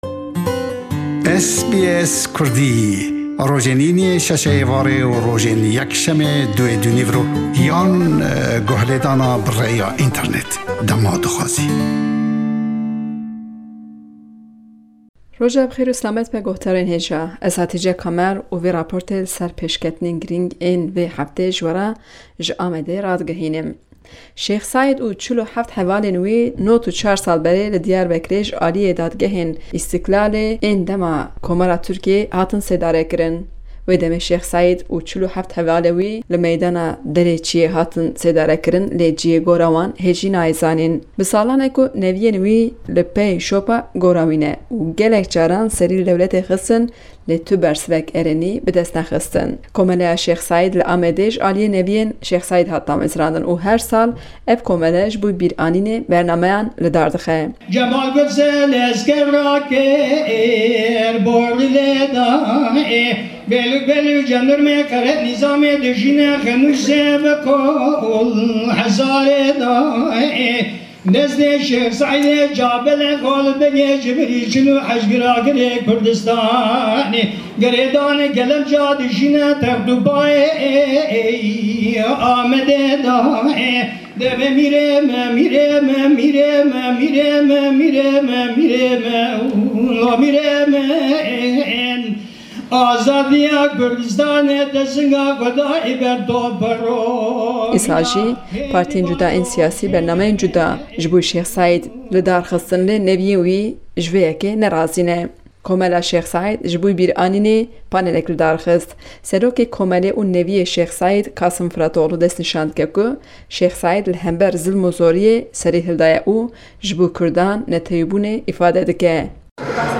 Li raporteka taybet ji Amedê
Hevepeyvînek bi nevîye Şêx Seîdê